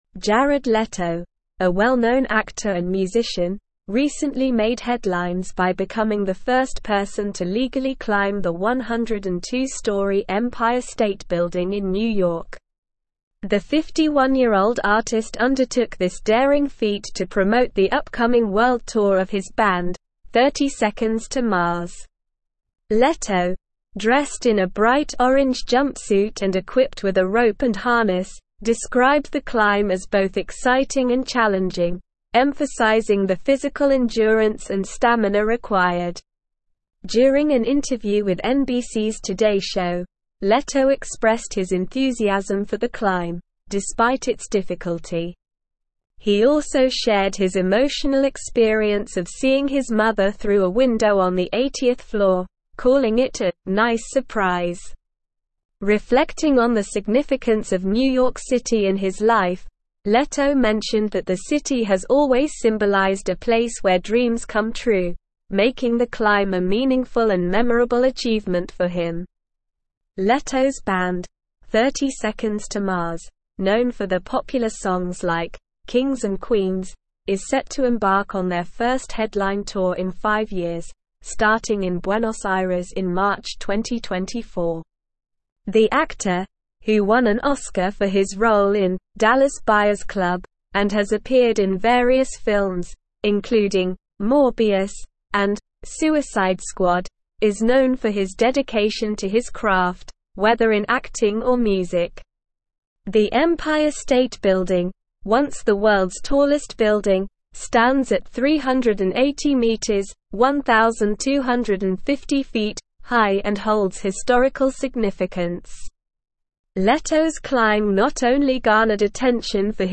Slow
English-Newsroom-Advanced-SLOW-Reading-Jared-Leto-Scales-Empire-State-Building-for-Band.mp3